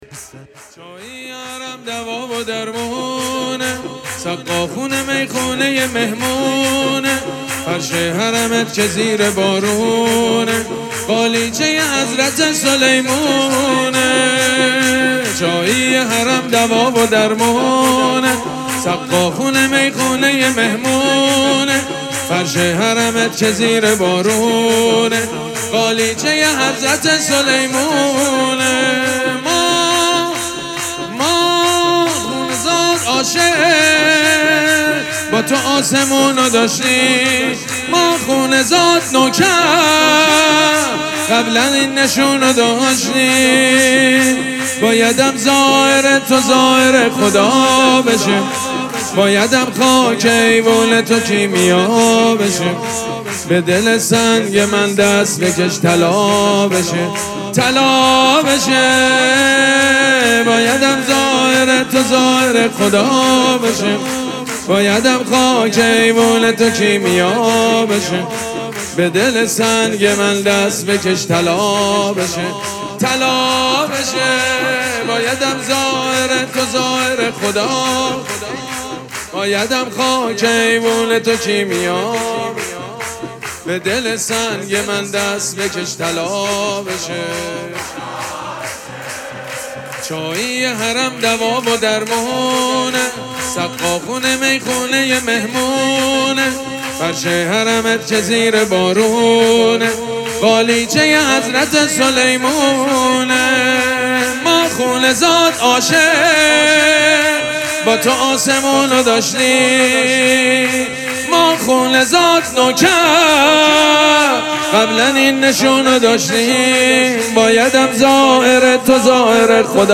مراسم جشن ولادت امام رضا علیه‌السّلام
حسینیه ریحانه الحسین سلام الله علیها
سرود
حاج سید مجید بنی فاطمه